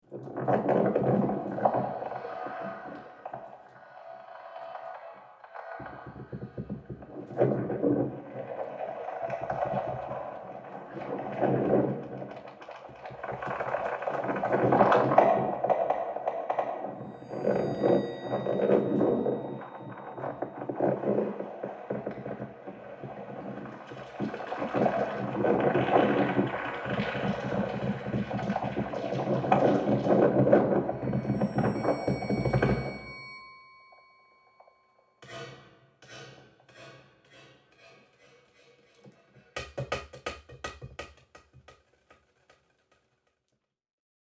My new work for laptop ensemble, Electric Monster and the Invasion of the Incredible B.S.O.s will premiere this Tuesday, May 4th at the Krannert Center for the Performing Arts Tryon Festival Theater in Urbana Illinois!!!
It’s one of those pieces where each performance is different because each player has a lot of leeway in the sounds and effects that they can use, and new juxtapositions of sound are constantly created.
B.S.O. soloists
laptops